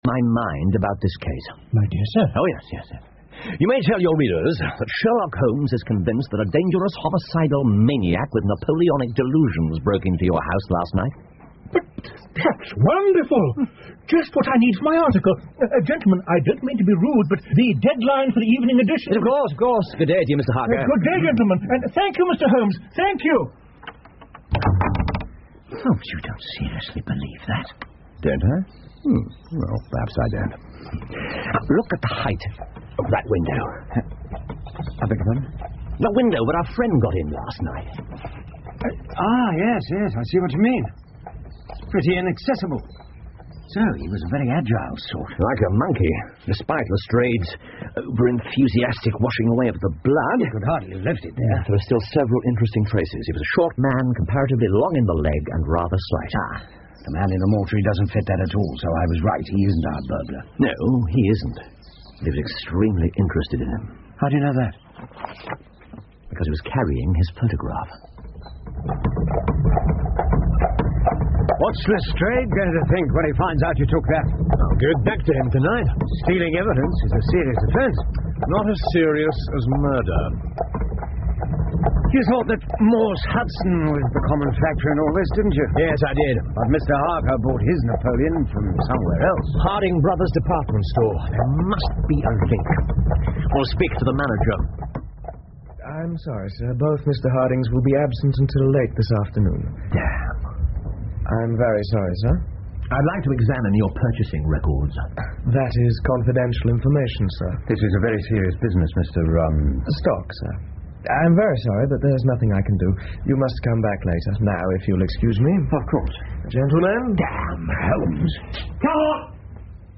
福尔摩斯广播剧 The Six Napoleons 5 听力文件下载—在线英语听力室